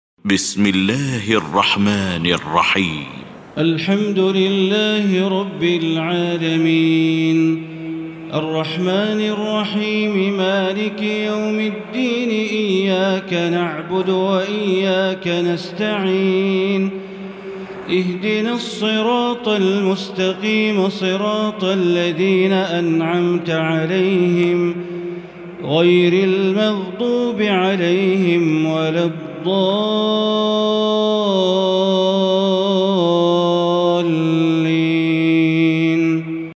المكان: المسجد الحرام الشيخ: معالي الشيخ أ.د. بندر بليلة معالي الشيخ أ.د. بندر بليلة الفاتحة